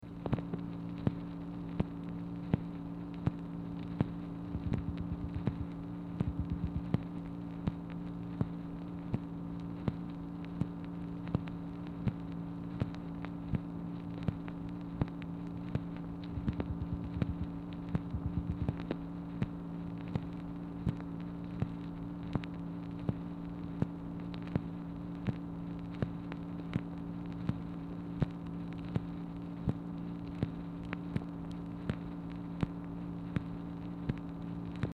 Telephone conversation # 2992, sound recording, MACHINE NOISE, 4/10/1964, time unknown | Discover LBJ
Telephone conversation
Dictation belt